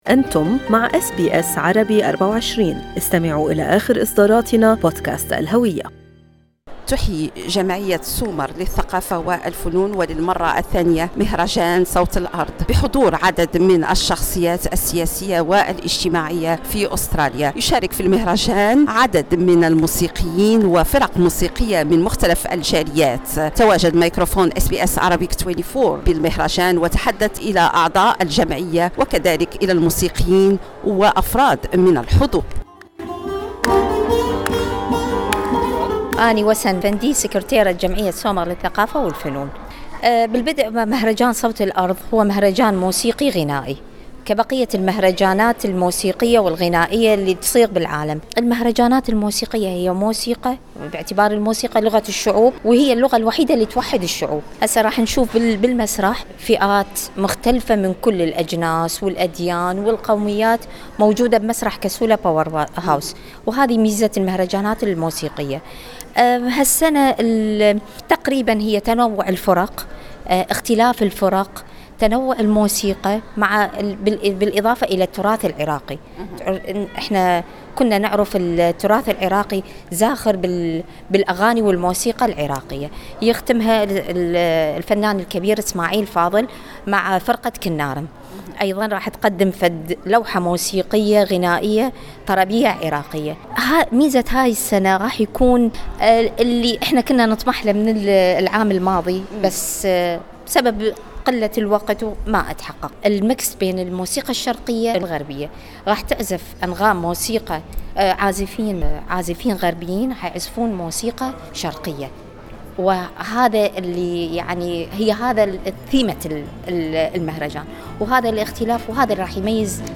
أحيت جمعية سومر للثقافة والفنون يوم السبت 25 يونيو/ حزيران مهرجان صوت الأرض في نسخته الثانية وهو مهرجان يجمع العديد من الفرق الفنية.